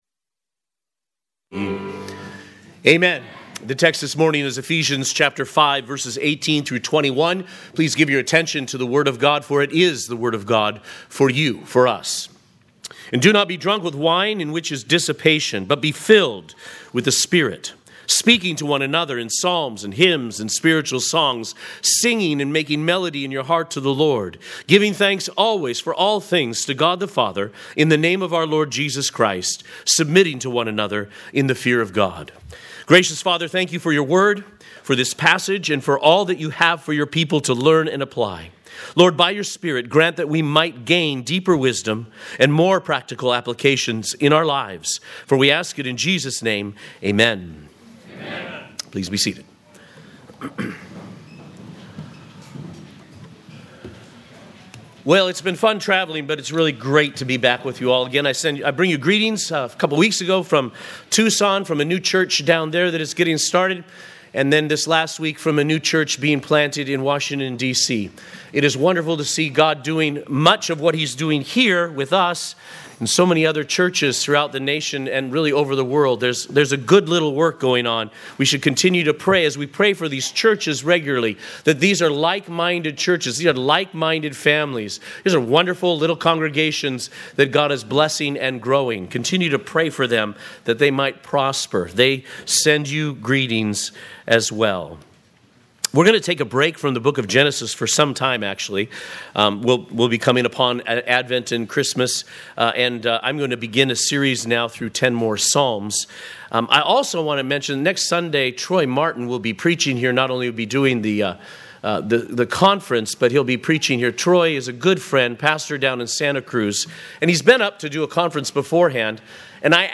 2025_11_02_Sermon.mp3